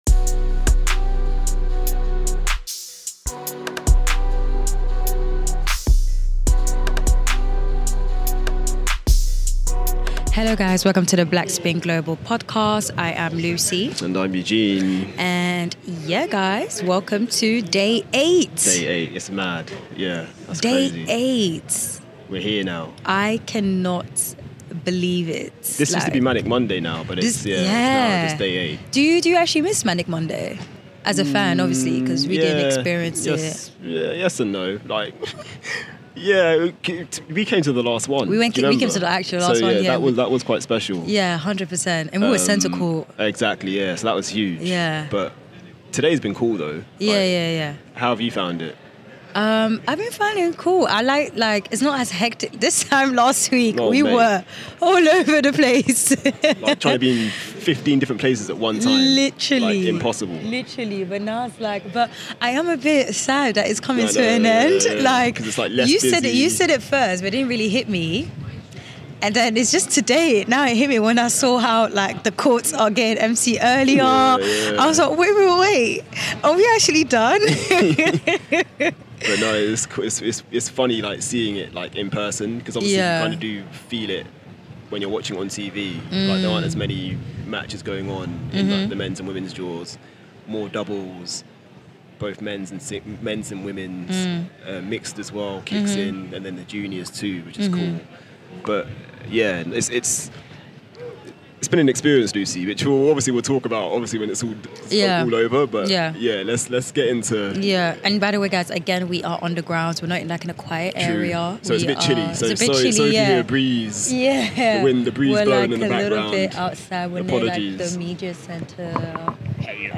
Interview snippets from Keys and Eubanks included.